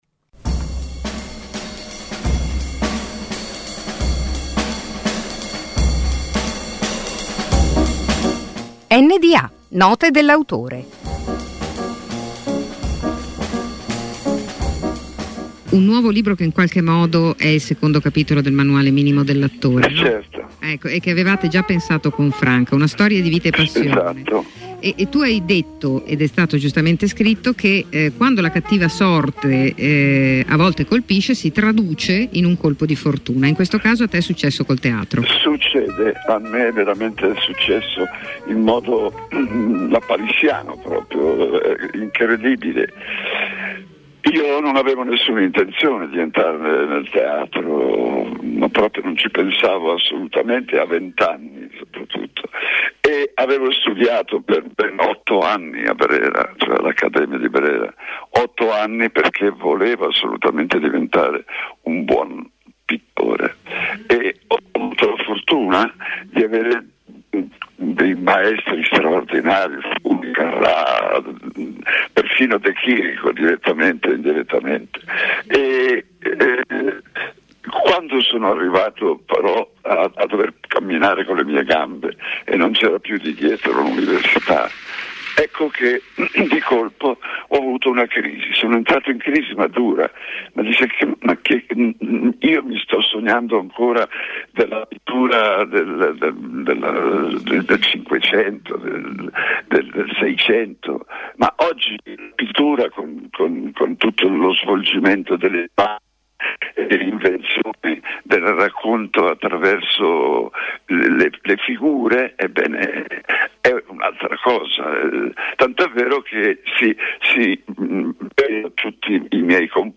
Un appuntamento quasi quotidiano, sintetico e significativo con un autore, al microfono delle voci di Radio Popolare. Note dell’autore è letteratura, saggistica, poesia, drammaturgia e molto altro.